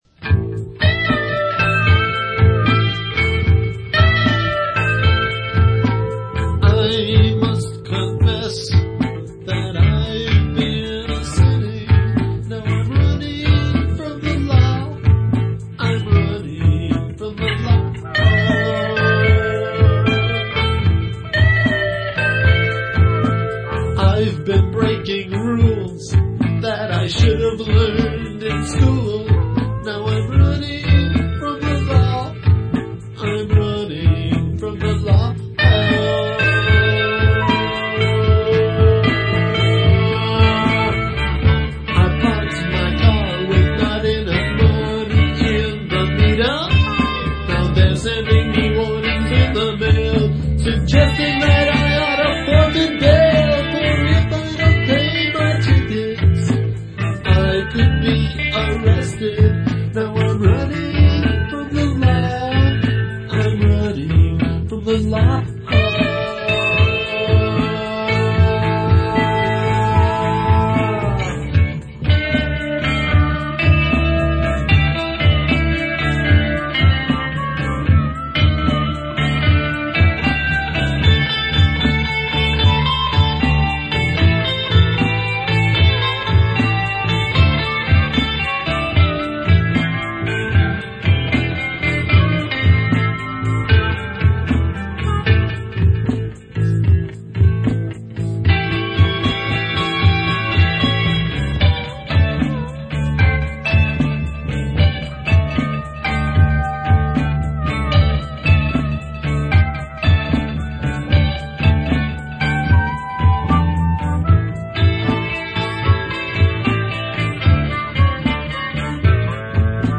Recorded on a 4-track deck in a residence hotel in the Mission district of San Francisco, this song will resonate with the throngs who have received a parking ticket in "The City".